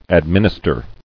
[ad·min·is·ter]